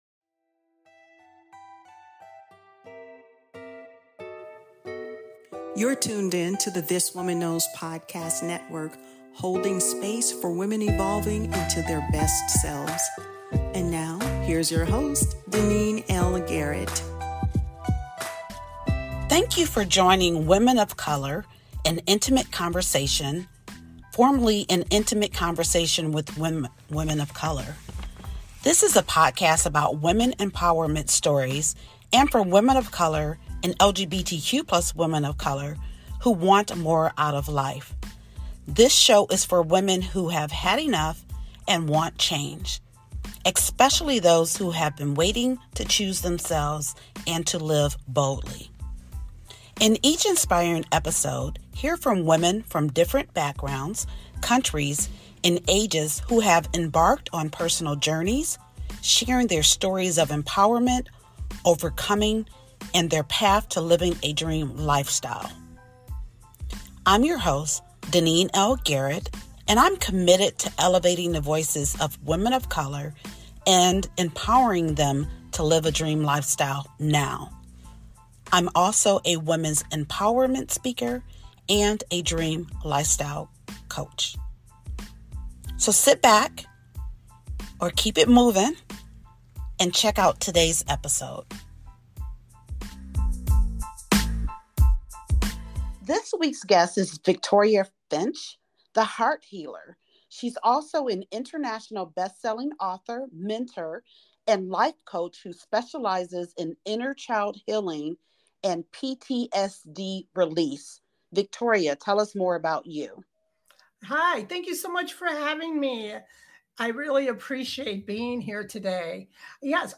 Whether you’re navigating motherhood, daughterhood, or the tender space in between, this 29-minute conversation offers wisdom and warmth.